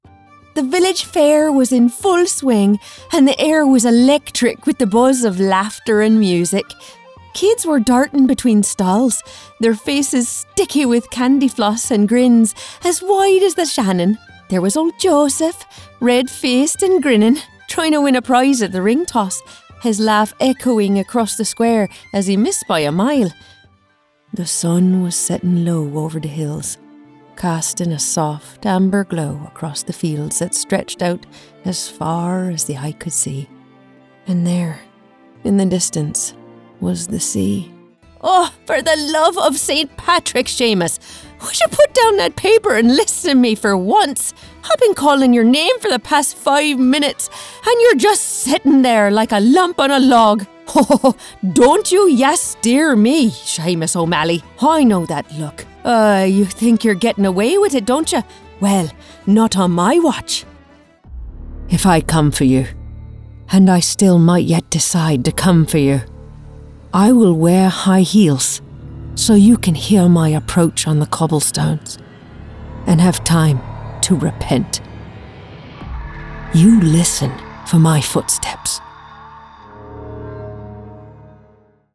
Character Sample (Irish Accent)
Irish Accent Demo Mixdown.mp3